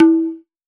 Index of /musicradar/essential-drumkit-samples/Hand Drums Kit
Hand Tabla 02.wav